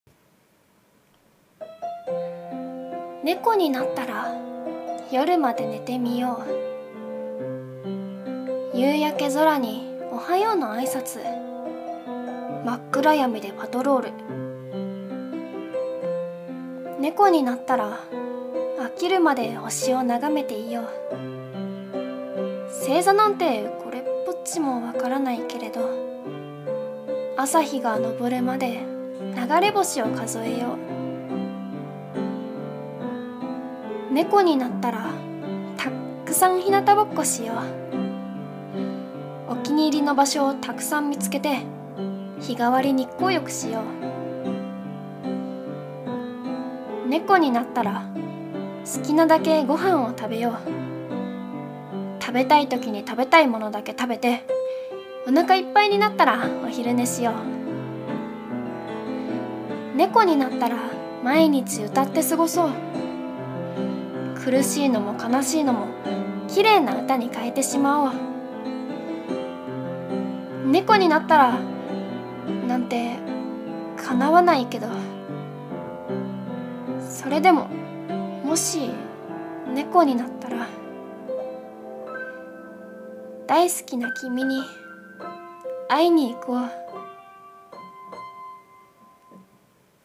【一人声劇】ねこになったら